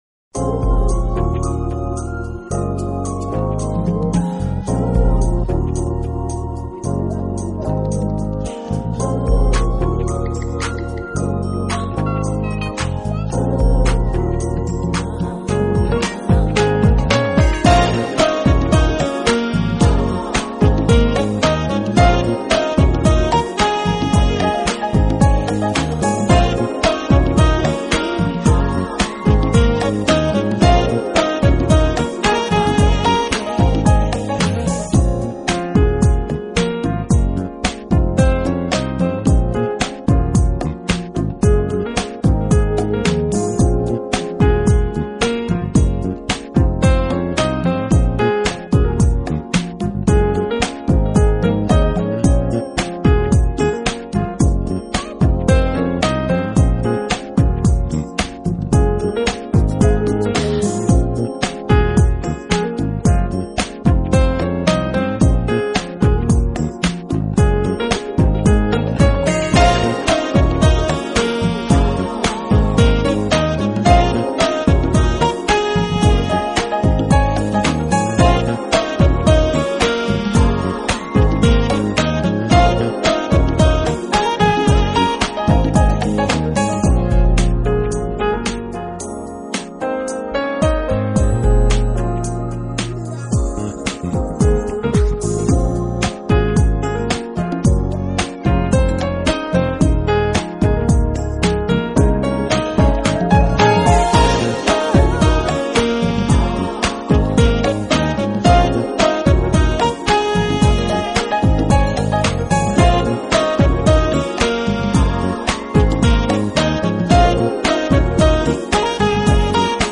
【爵士钢琴】